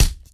hit0.ogg